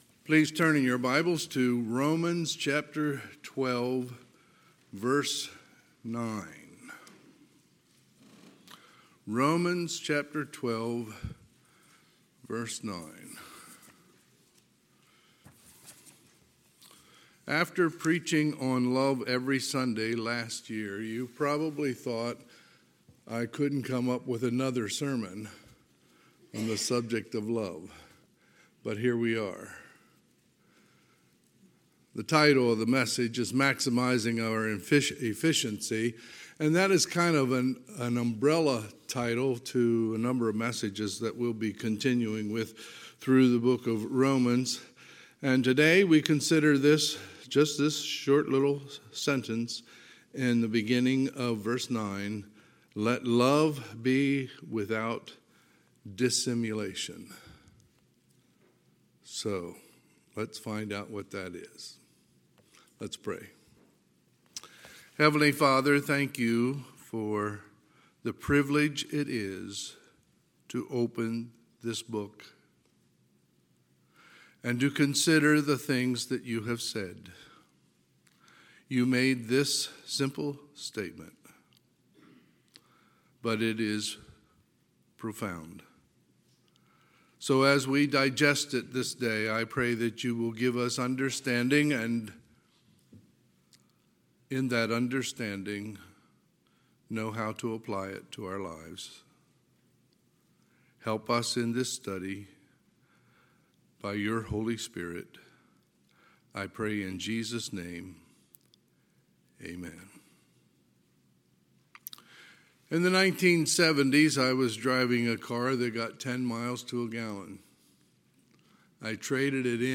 Sunday, July 24, 2022 – Sunday AM
Sermons